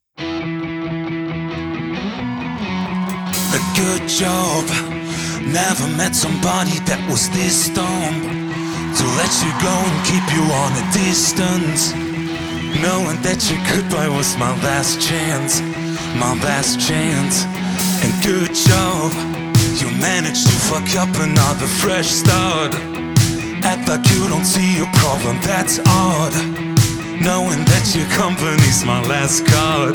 Жанр: Поп музыка
Pop